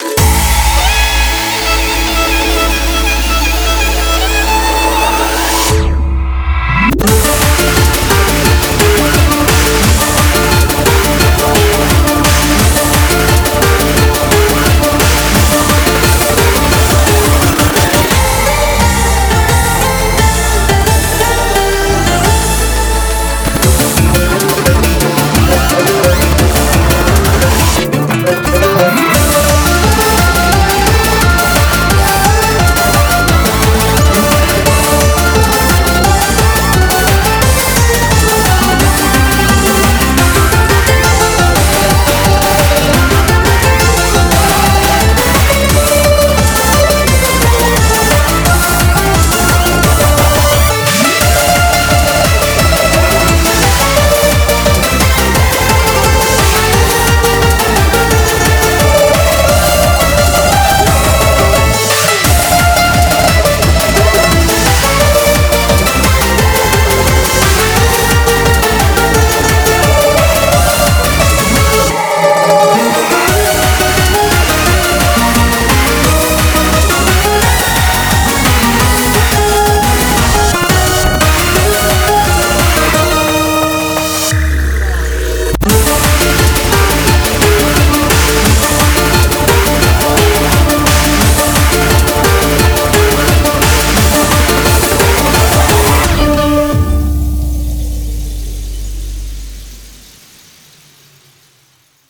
BPM87-174
Audio QualityPerfect (High Quality)